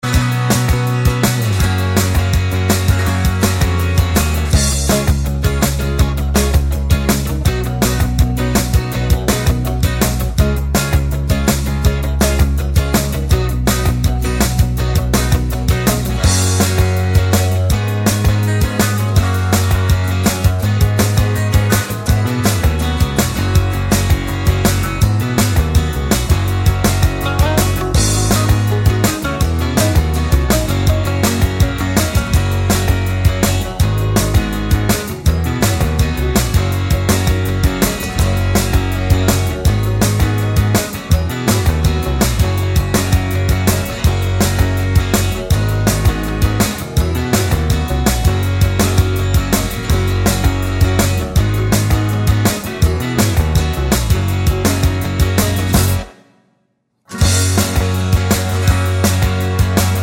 for duet Pop (1990s) 3:36 Buy £1.50